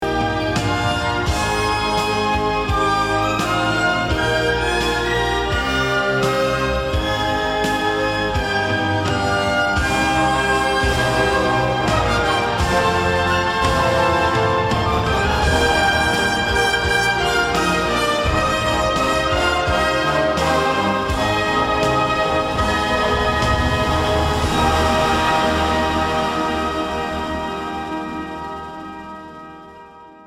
key: Eb Mayor